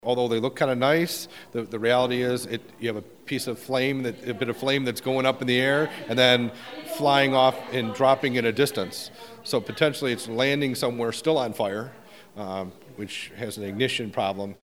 Rep. Yaroch talks about his new legislation that would require an individual setting off fireworks to be at least 200 feet from a building they do not own.  The plan also gives local communities the option to regulate the use of sky lanterns.